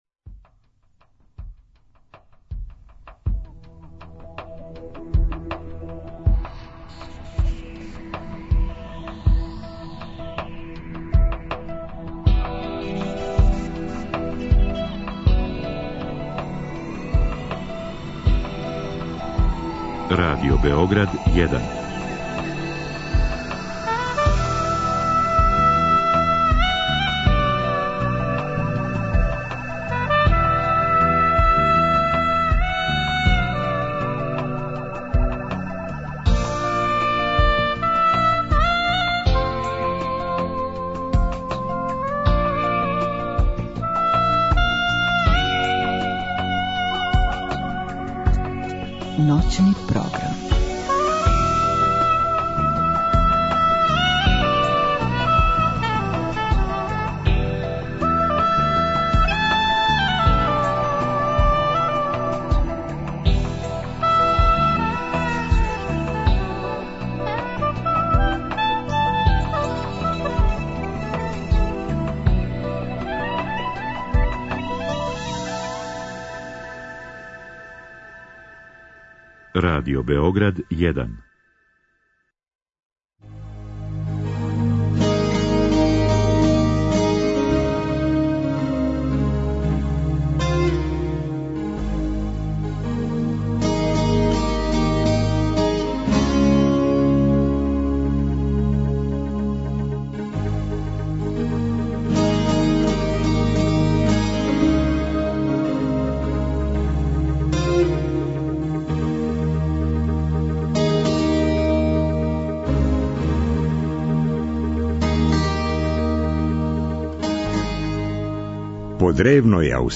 И наравно, слушамо добру музику из тог времена.